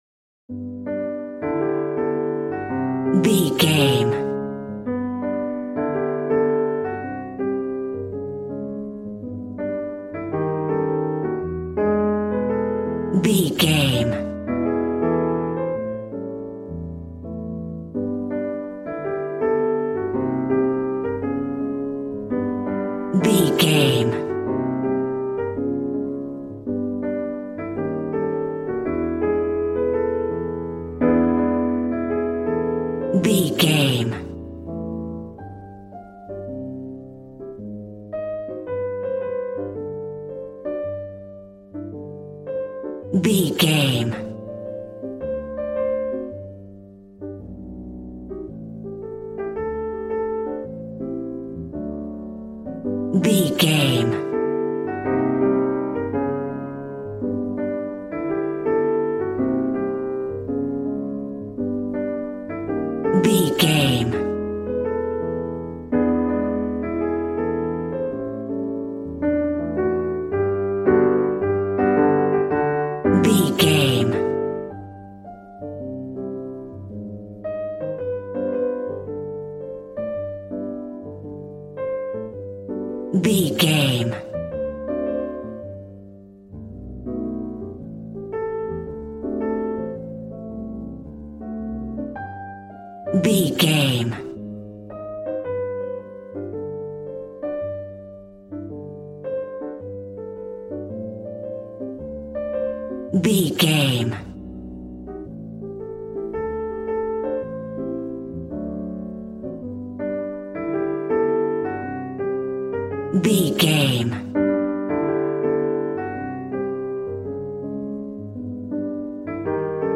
Smooth jazz piano mixed with jazz bass and cool jazz drums.,
Aeolian/Minor